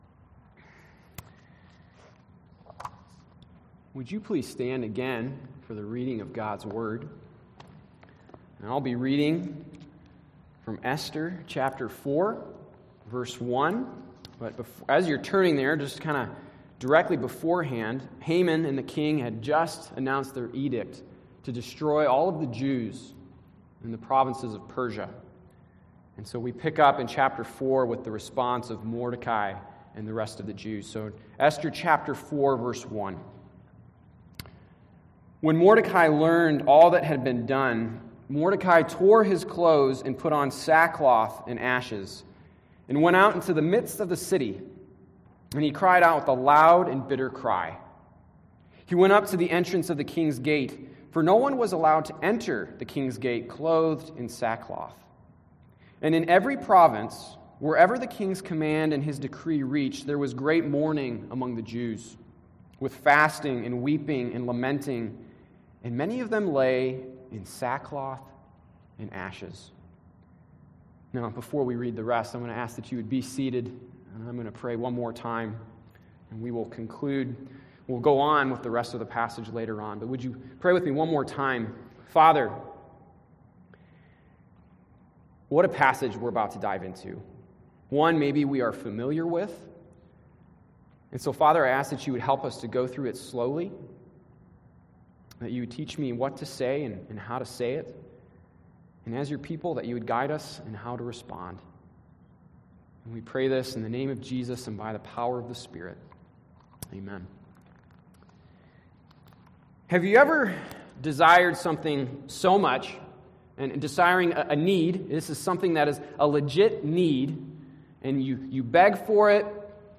July 25, 2021 Worship Service
Who are You Walking With Passage: Esther 4 Service Type: Live Service Download Files Notes and/or Discussion Questions « Who are you Walking With?